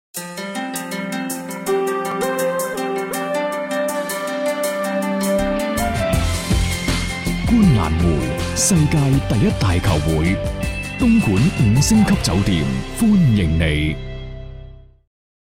男粤20_广告_片头片花_东莞酒店.mp3